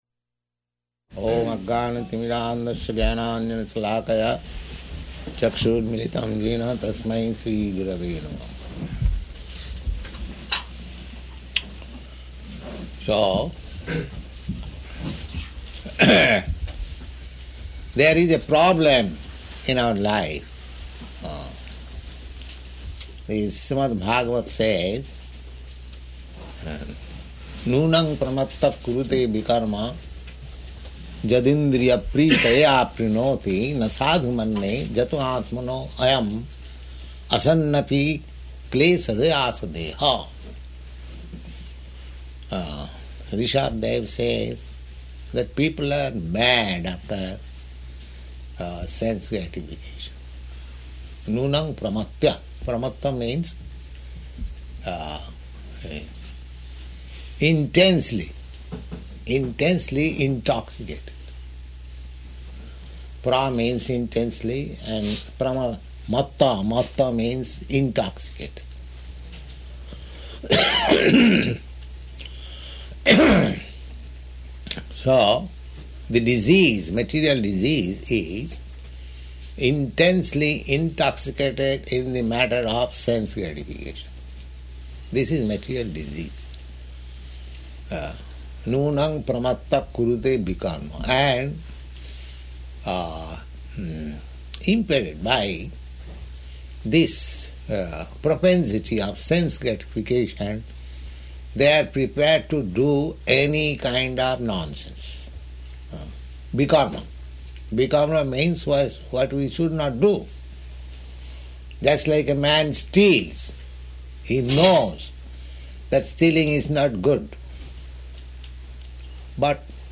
Type: Initiation
Location: New Vrindavan